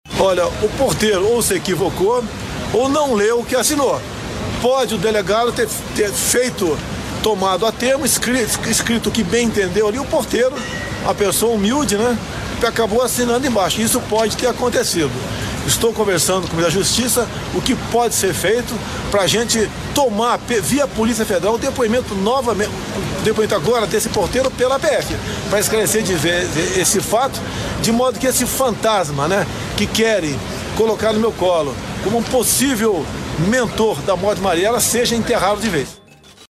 Presidente Bolsonaro rebate citação de porteiro